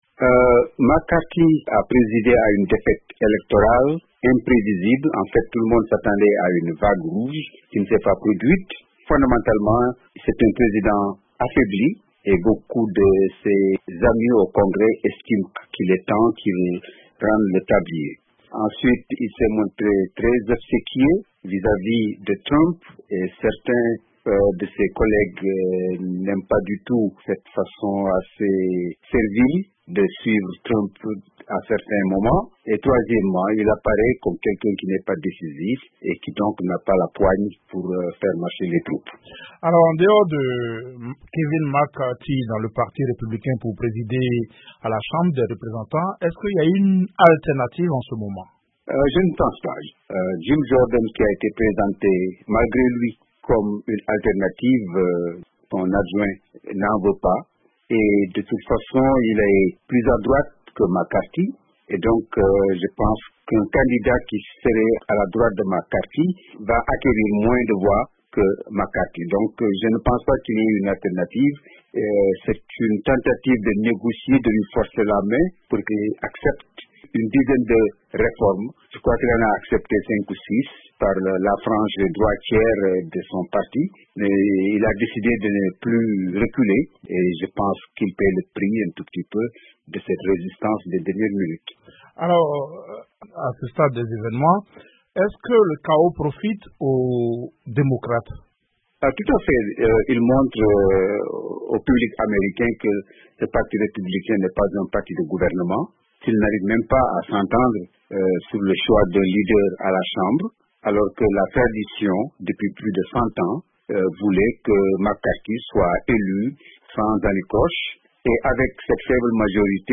Jamais, en un siècle, la Chambre n'avait échoué à élire son speaker dès le premier vote. L’historien et analyste politique
joint à New York